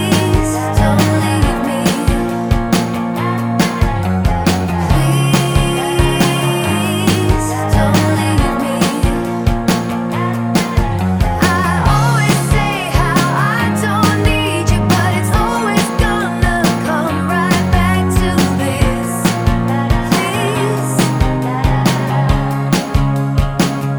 no Backing Vocals Rock 3:54 Buy £1.50